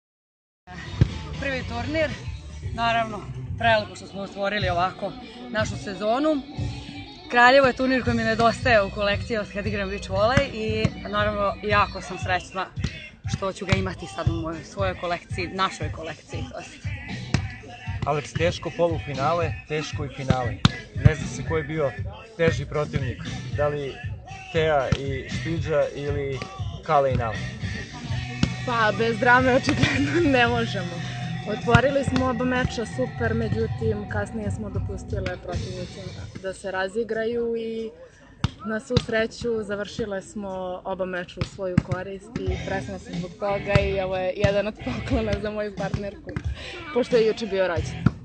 IZJAVE